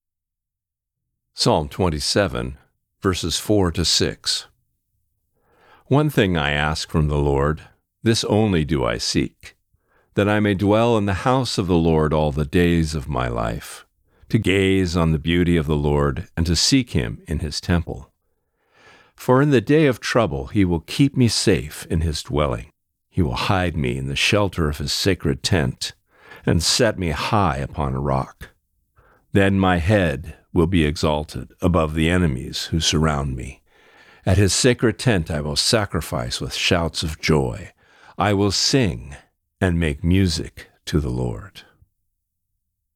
Reading: Psalm 27:4-6